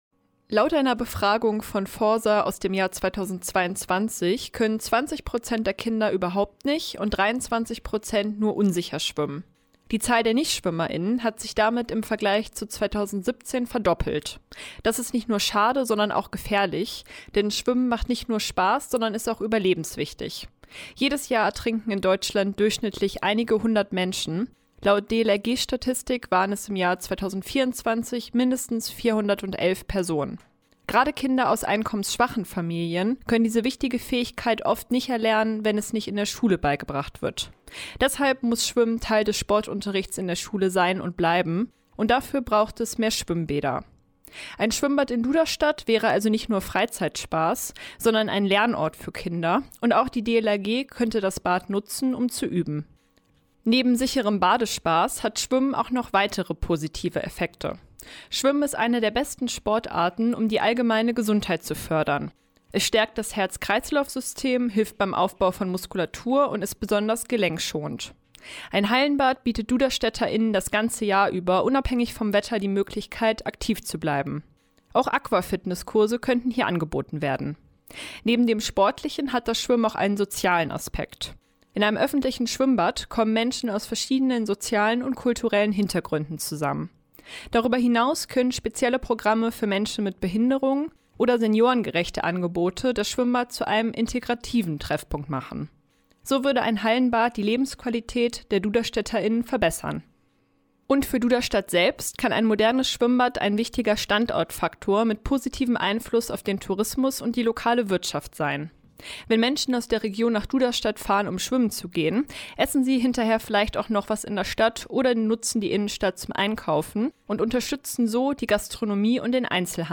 Sendung: Mittendrin Redaktion Kommentar